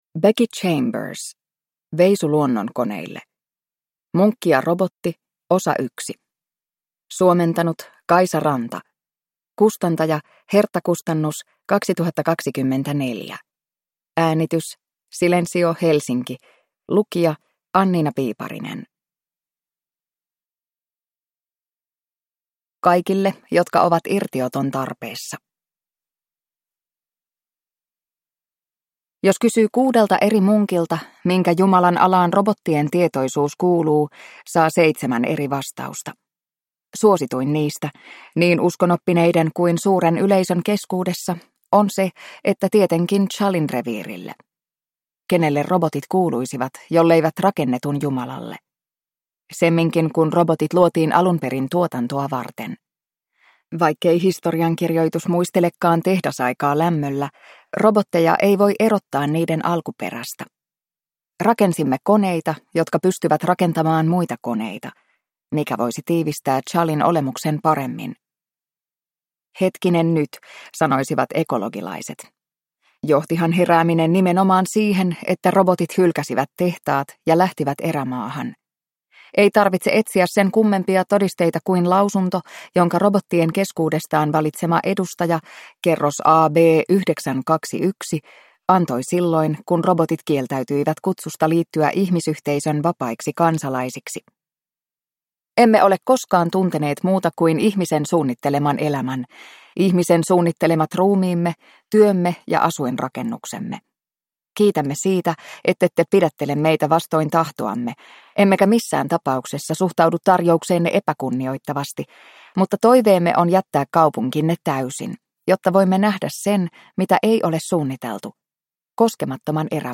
Veisu luonnonkoneille (ljudbok) av Becky Chambers